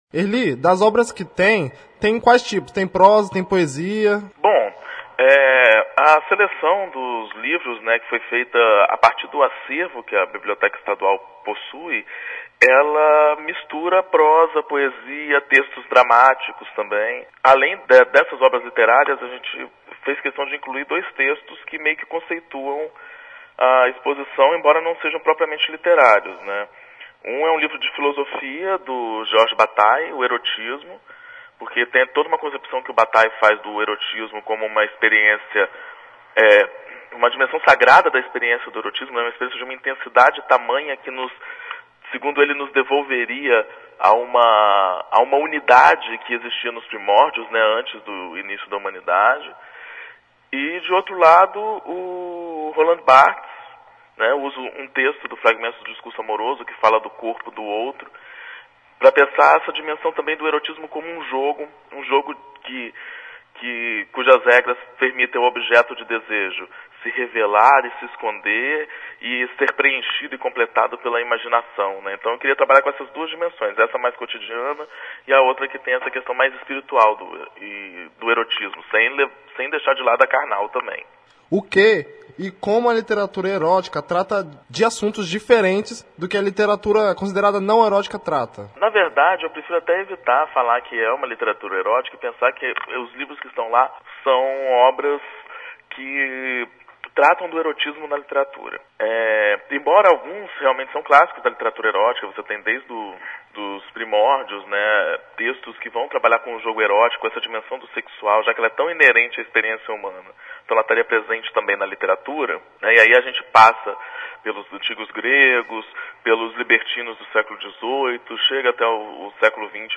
SERVIÇO Exposição Literatura e Erotismo Biblioteca Pública do Espírito Santo Visitação: de segunda a sexta-feira, até 28 de junho Horário: 8 às 19 horas Entrada franca Ouça a entrevista.